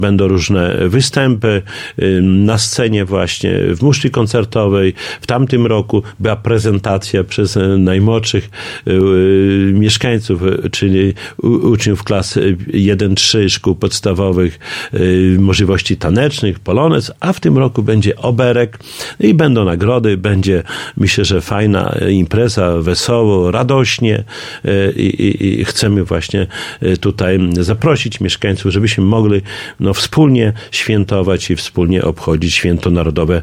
Na uroczystości zaprasza Czesław Renkiewicz, prezydent Suwałk.